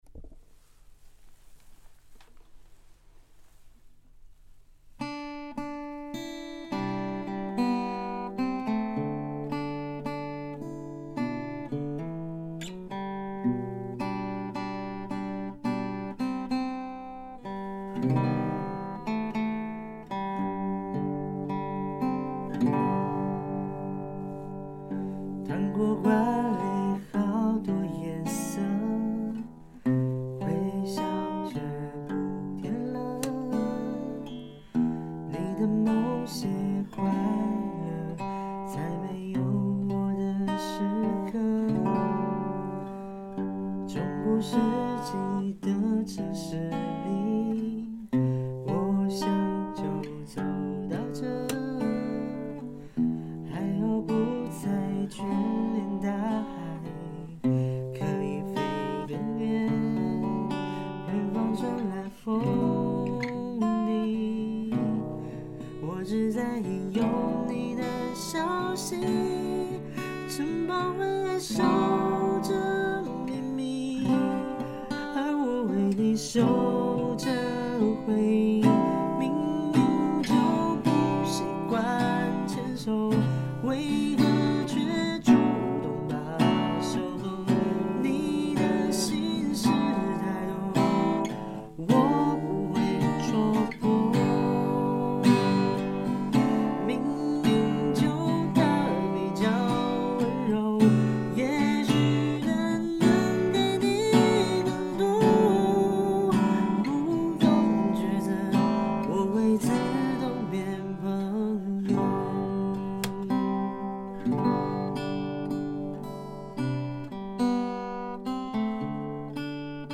G调指法